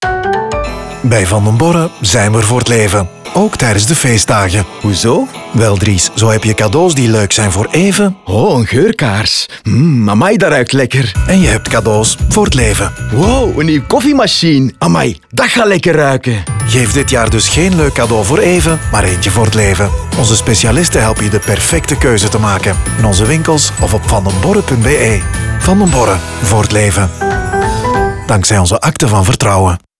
Radio
Radio Production: Sonhouse